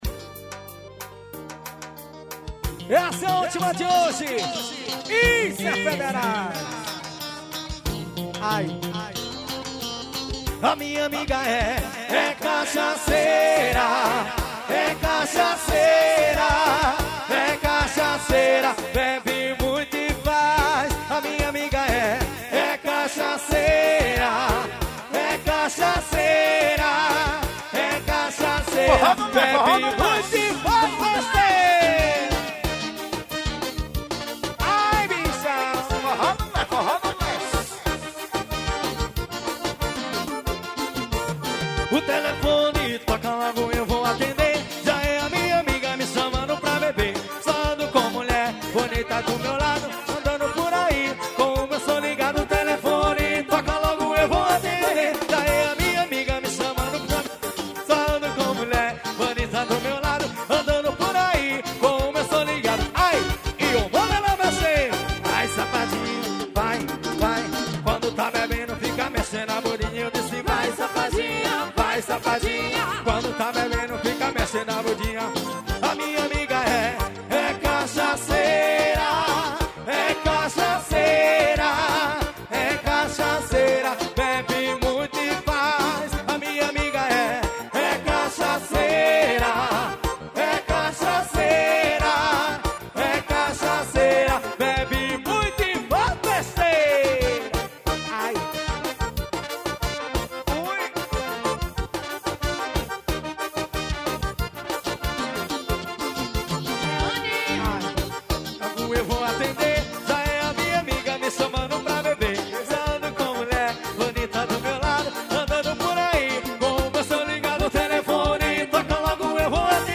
forró estourado.